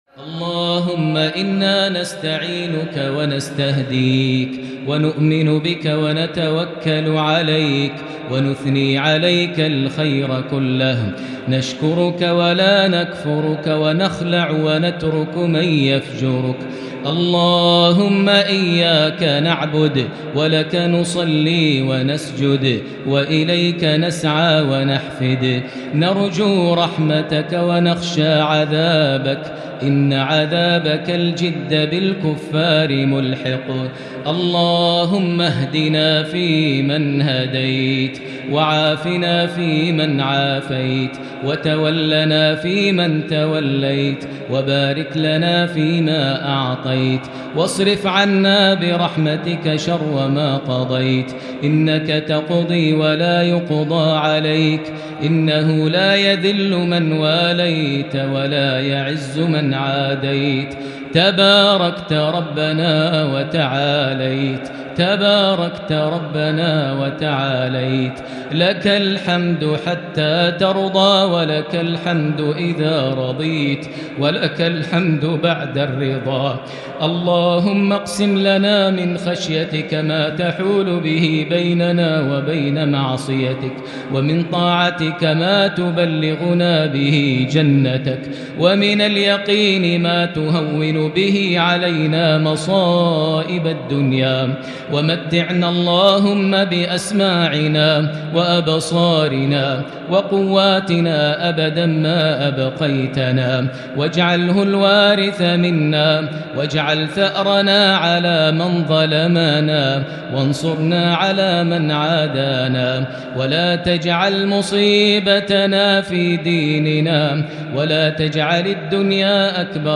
دعاء القنوت ليلة 3 رمضان 1442هـ | Dua for the night of 3 Ramadan 1442H > تراويح الحرم المكي عام 1442 🕋 > التراويح - تلاوات الحرمين